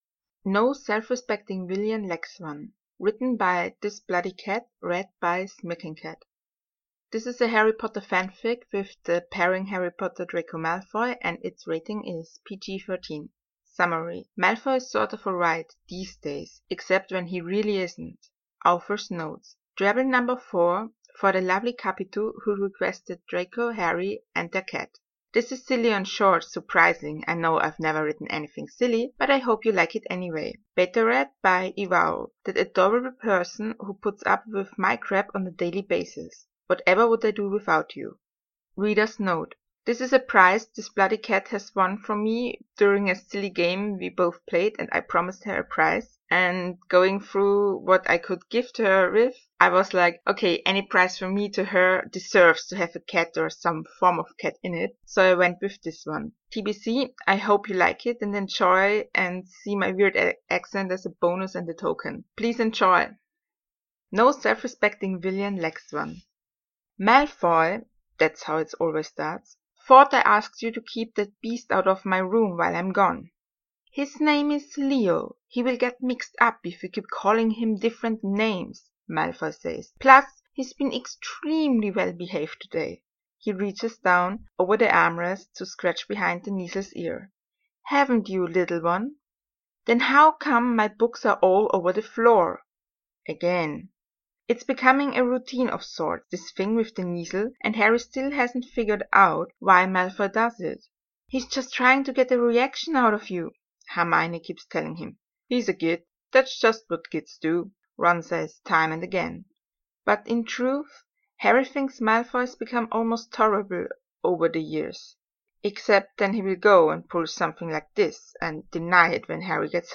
Warnings:reader has a very weird accent!